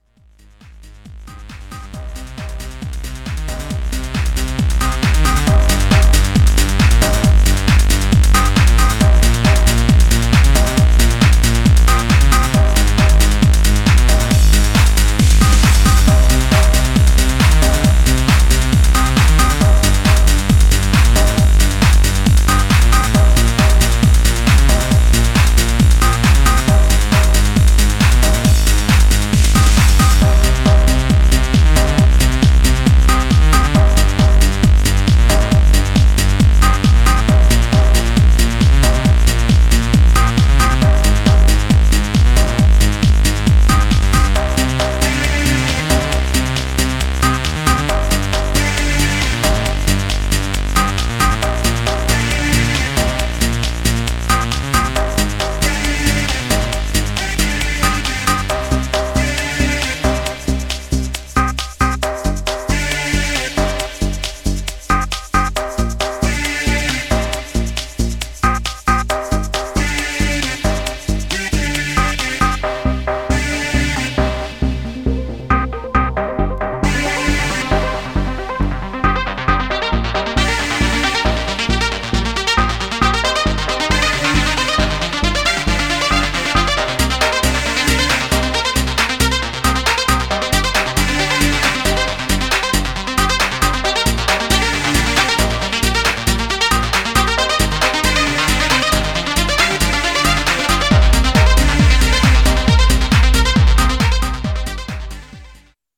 Styl: Progressive, House, Techno, Trance